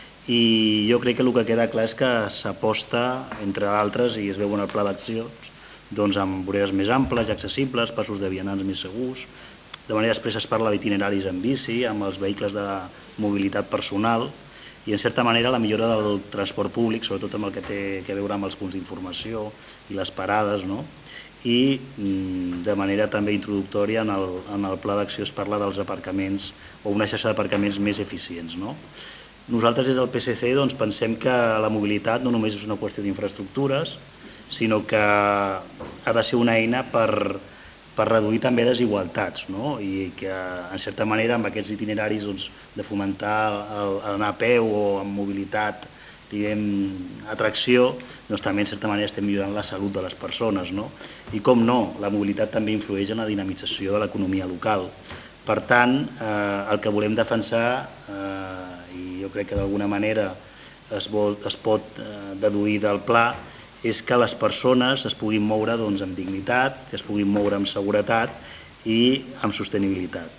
PLe Municipal. Juliol de 2025
Javier González, portaveu PSC i regidor de Transició Digital i Sostenible